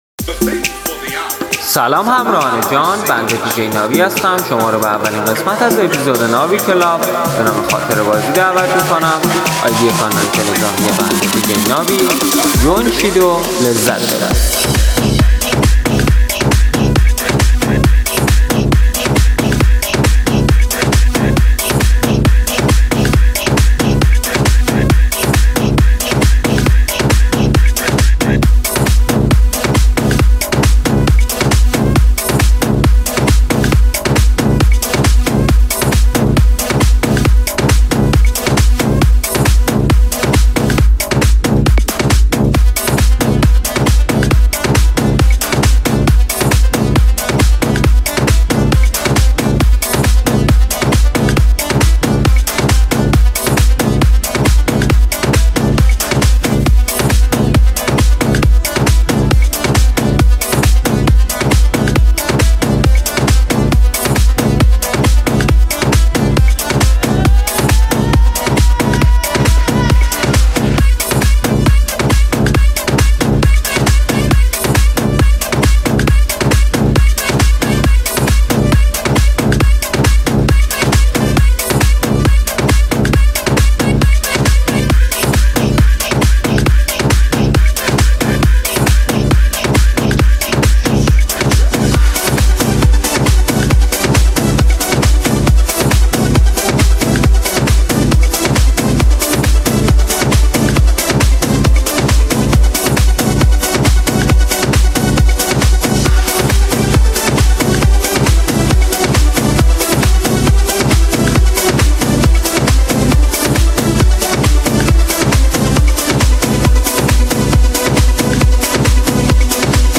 دانلود ریمیکس طولانی
گلچین آهنگ های ایرانی